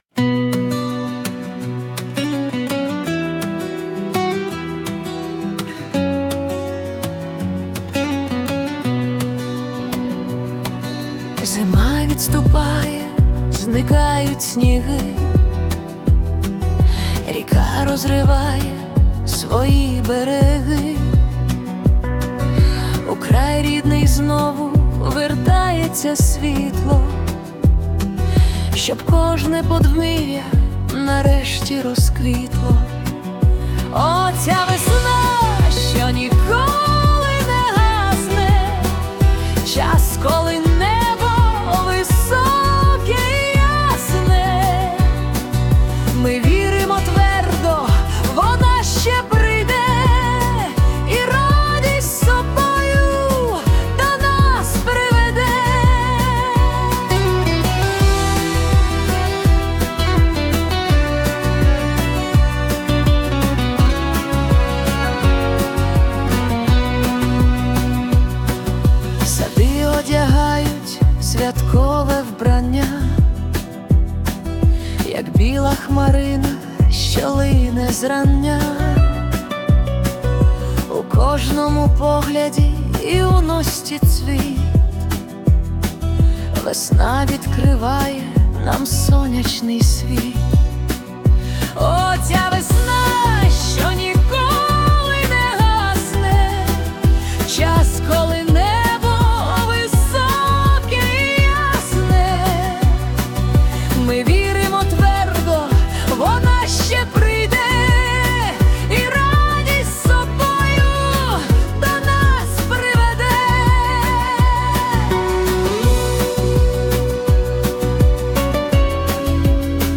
🎵 Жанр: Акустичний поп-рок